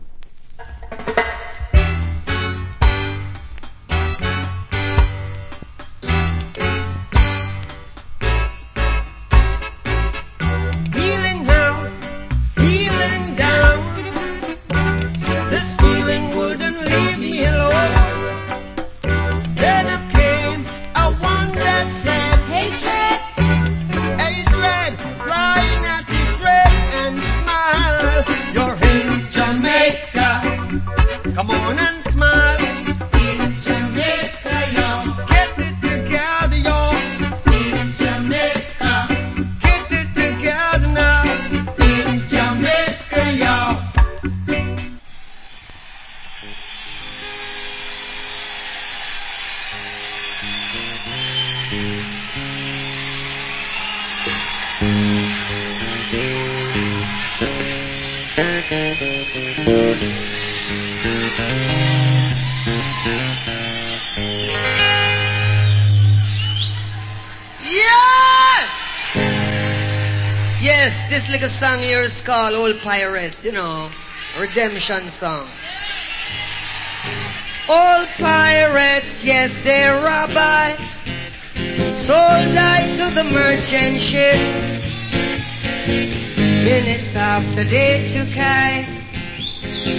Roots Rock Vocal.